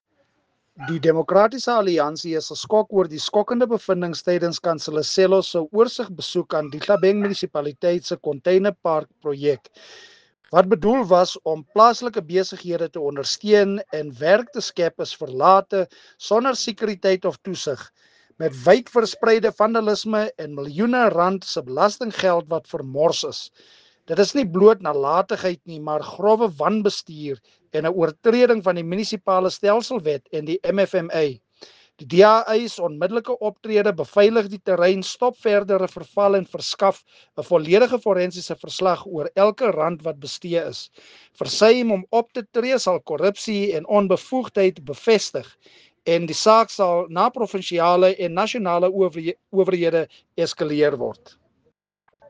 Afrikaans soundbite by Cllr Marius Marais with images here, here, here, and here